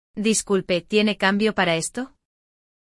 Um diálogo entre um garoto e uma vendedora
Ouça um diálogo autêntico em espanhol entre um garoto e uma vendedora no Peru e aprimore sua pronúncia com o Walk ‘n’ Talk Essentials!